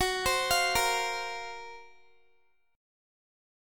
Listen to GbM7b5 strummed